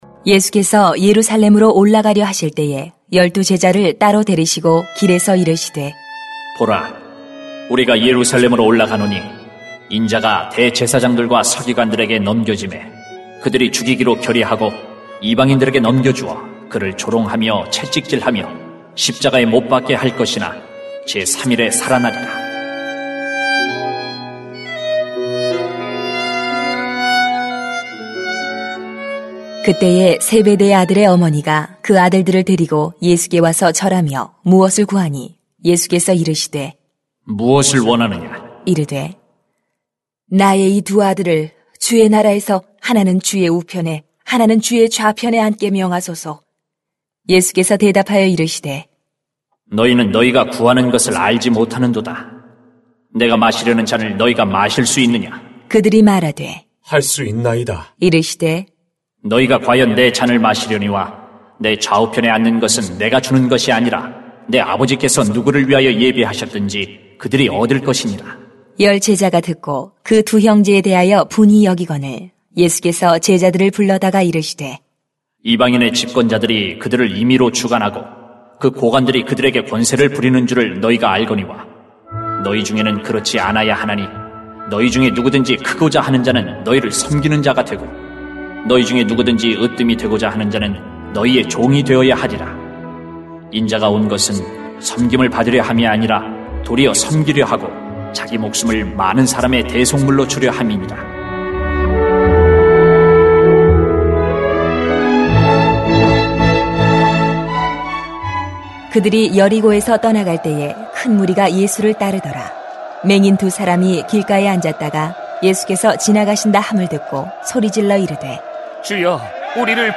[마 20:17-34] 섬기는 자리에 서야합니다 > 새벽기도회 | 전주제자교회